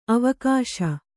♪ avakāśa